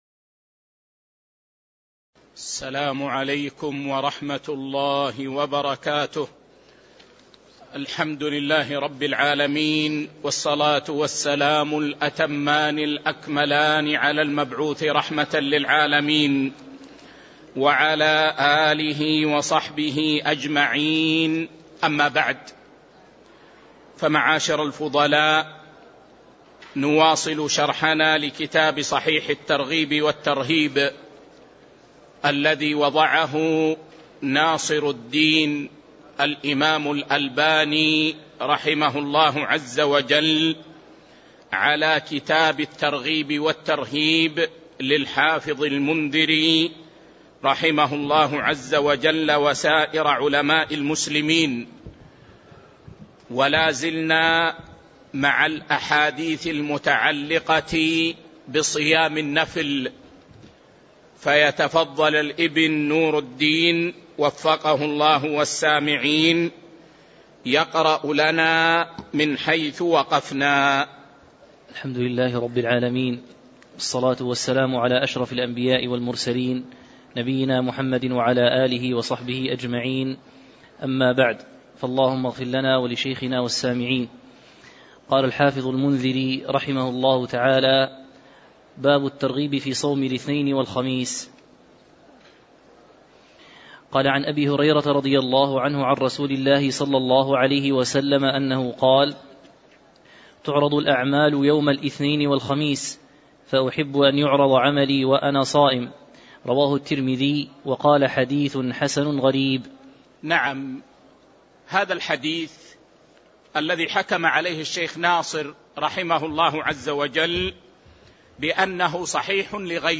تاريخ النشر ٢٢ شوال ١٤٤٥ هـ المكان: المسجد النبوي الشيخ